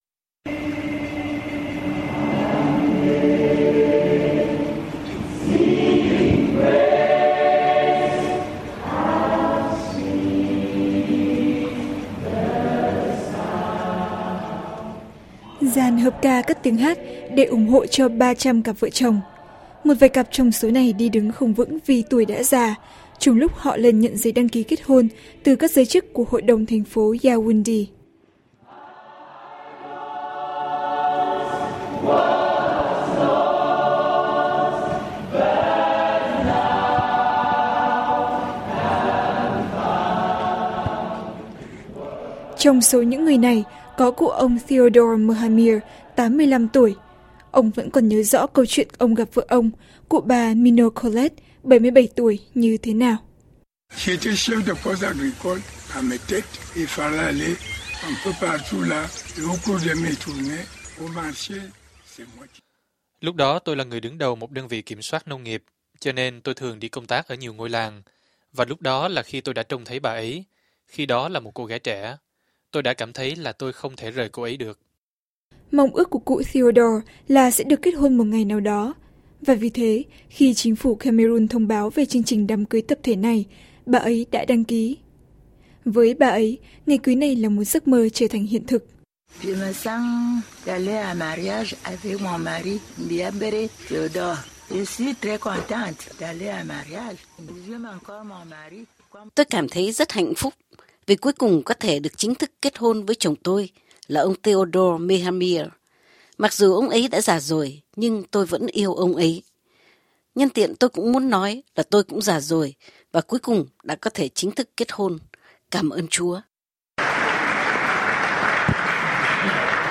Dàn hợp ca cất tiếng hát để ủng hộ cho 300 cặp vợ chồng.